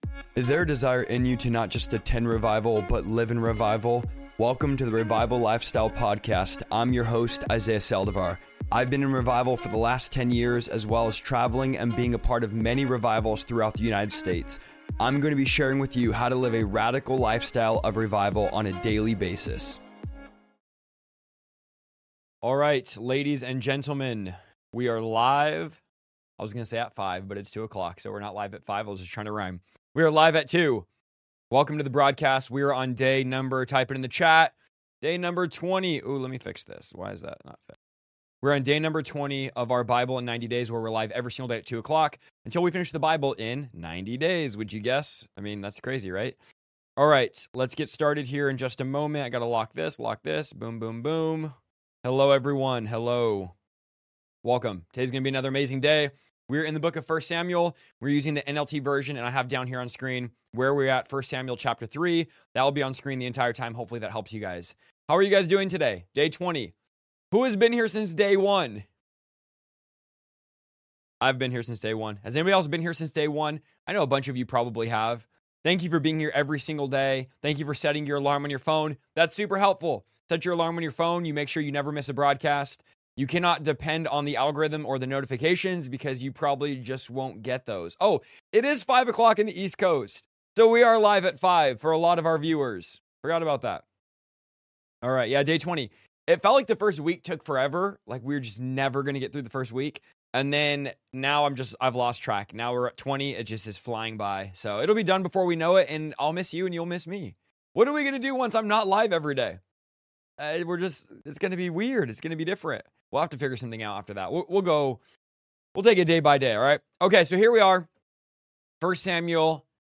I am going to be LIVE everyday at 2 PM for 90 days straight reading through the entire Bible!